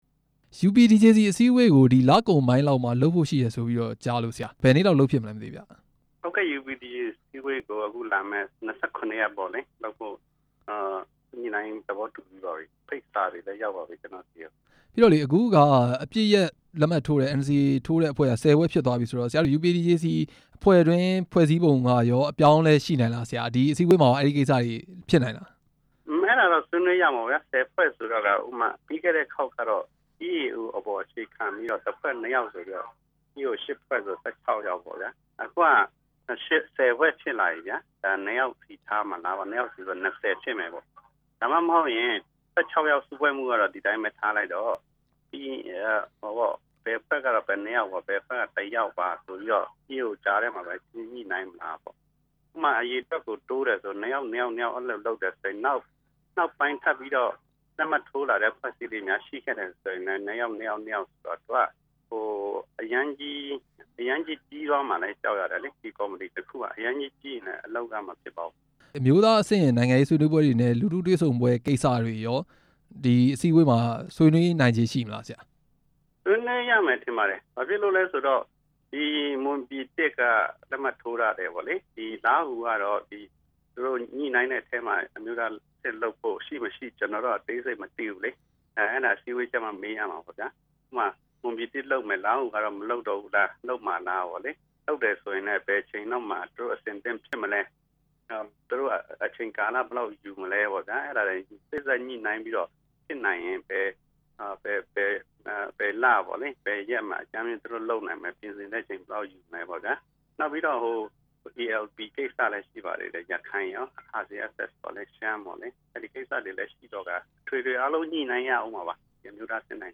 UPDJC မှာ ဆွေးနွေးမယ့် အကြောင်းအရာတွေနဲ့ ပတ်သက်ပြီး မေးမြန်းချက်